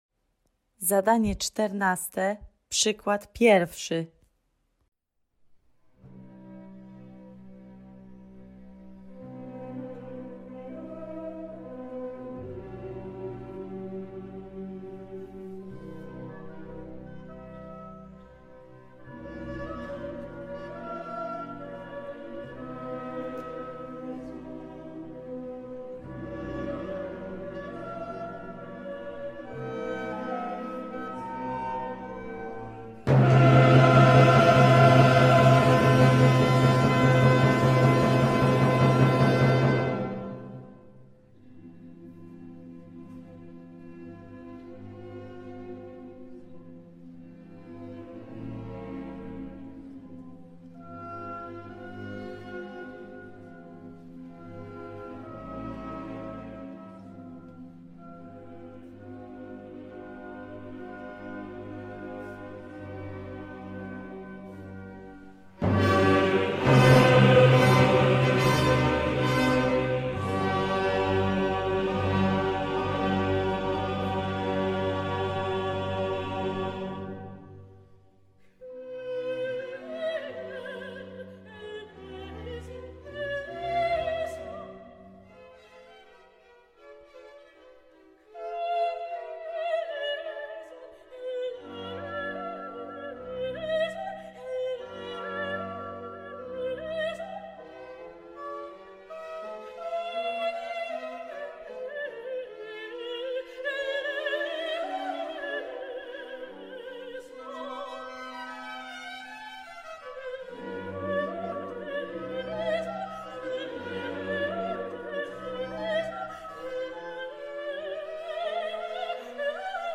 2. Klarnet – zagraj cicho nutę B bez ustnika
3. Wiolonczela – uderz prawą ręką w brzuch, a lewą w boki
8. Fortepian – uderzaj dłonią w struny
13. Głos – wypowiedz głośno dźwięk „ch”